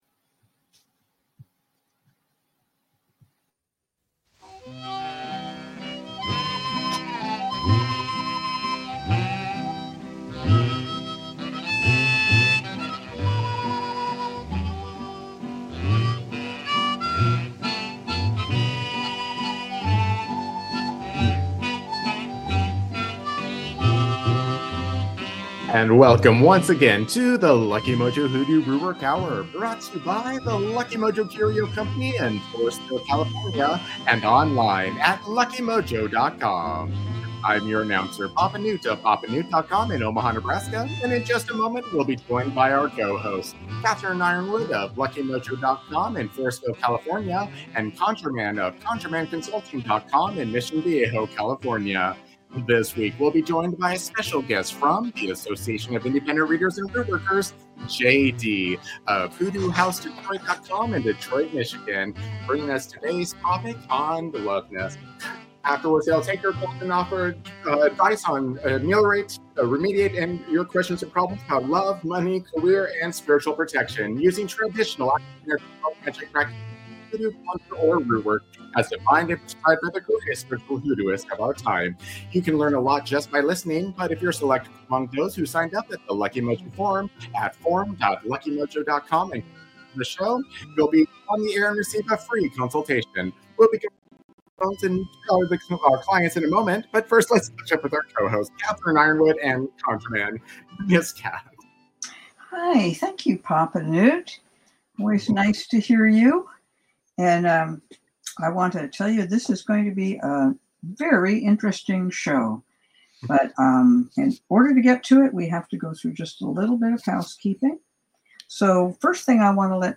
It's the longest-running hoodoo conjure rootwork radio show in the UNIVERSE!